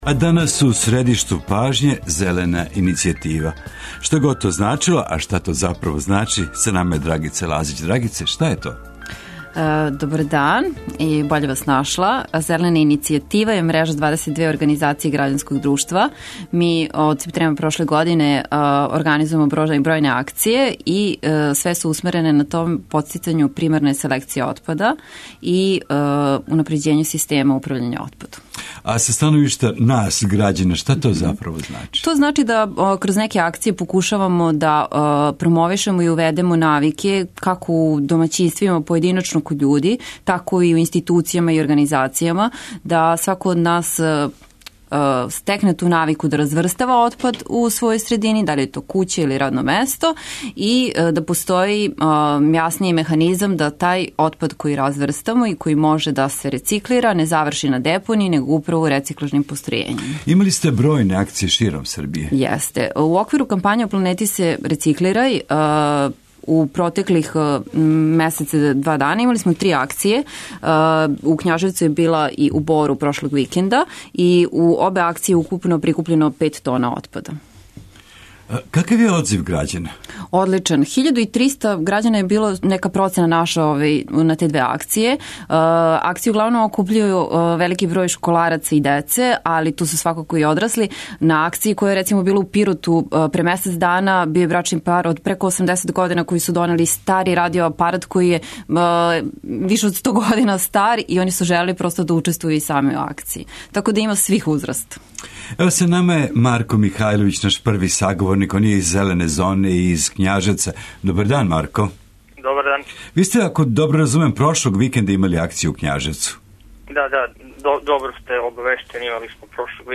Радио Београд 1, 17.00